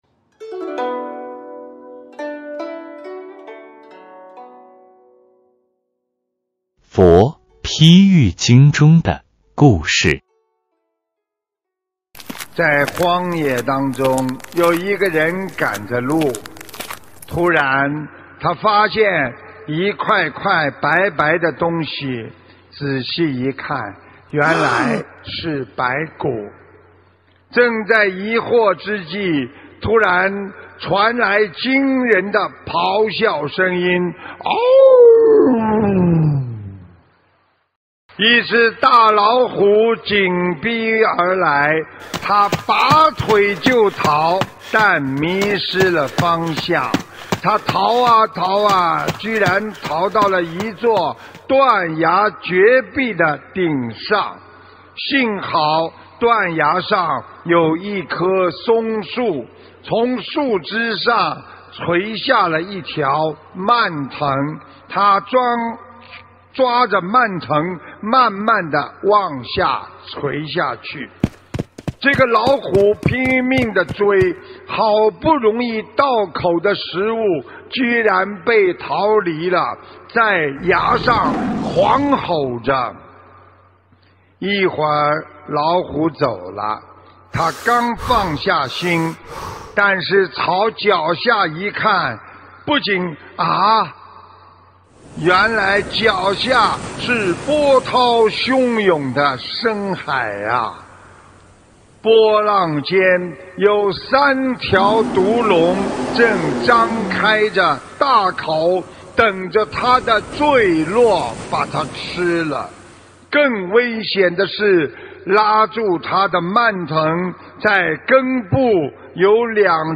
音频：《佛譬喻经》中的故事！师父讲故事！摘自_2019年10月20日.马来西亚吉隆坡智慧妙语！